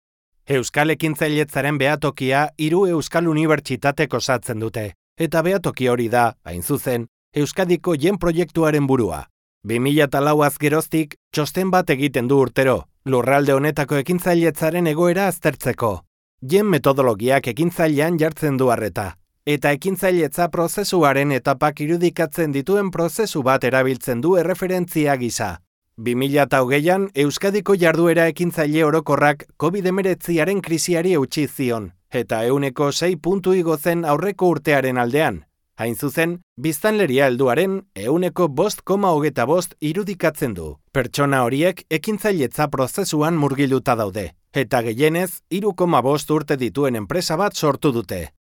some of my jobs as online castilian spanish and basque voiceover
02Elearning EUSK - GEM País Vasco